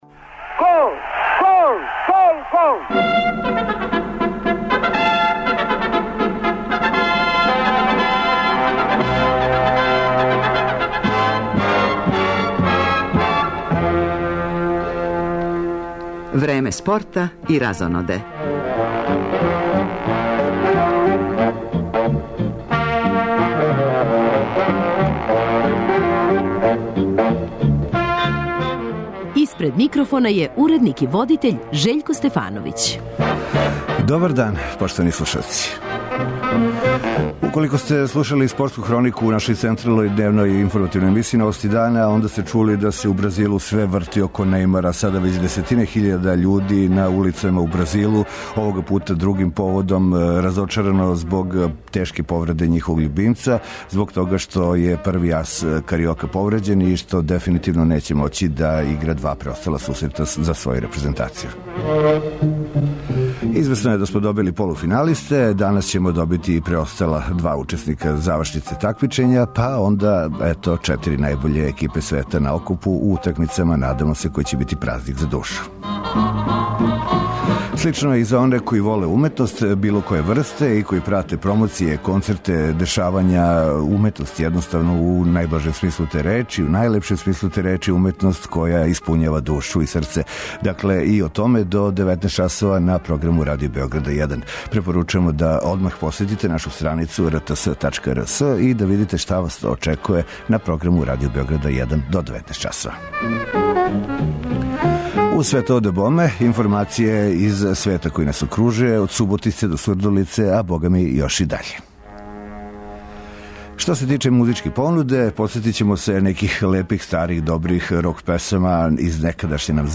У гостима су нам чланови групе Рио, са неколико нових композиција које ће премијерно представити нашим слушаоцима.